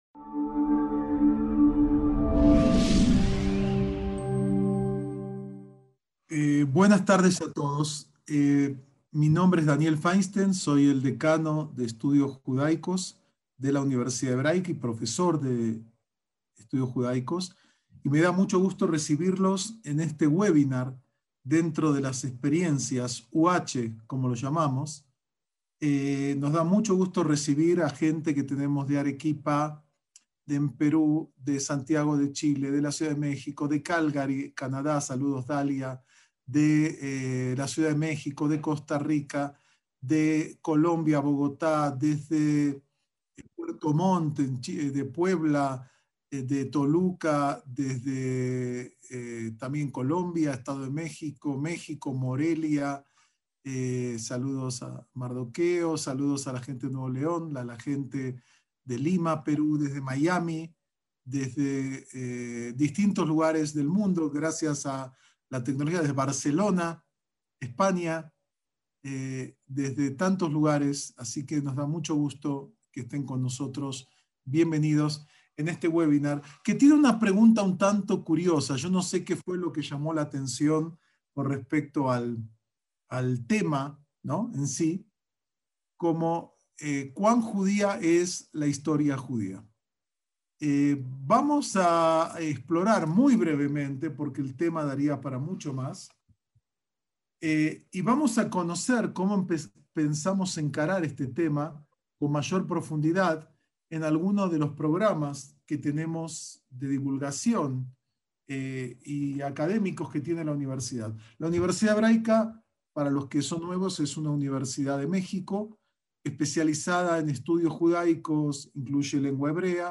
ACTOS "EN DIRECTO" - ¿Es la historia judía diferente a las demás? ¿Cuáles fueron los momentos clave y las encrucijadas que definieron el desarrollo del judaísmo? En este curso del 2020 se analizó la historia real enfrentándola a la historia dogmática.